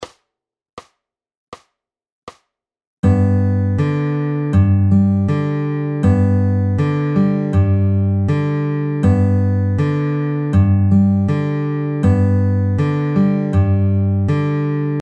We take out that second pinch and add another in-between note.
Add a second in-between note